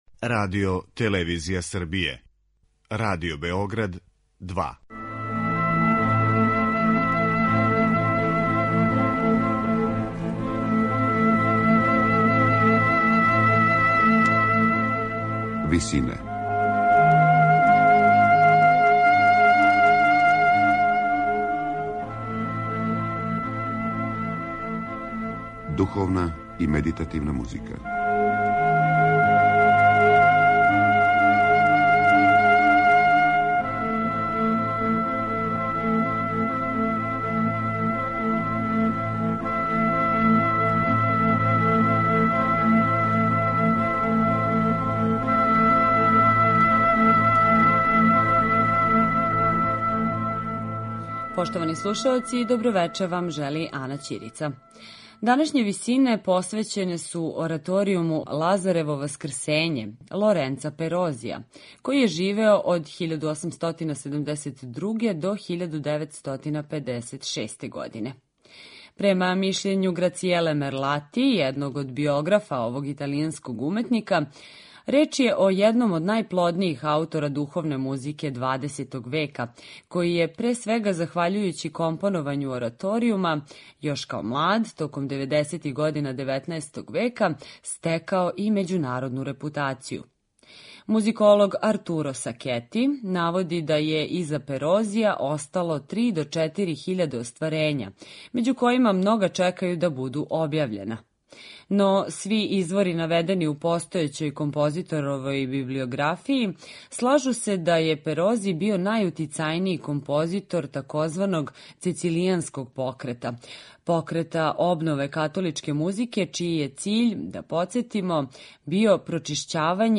медитативне и духовне композиције аутора свих конфесија и епоха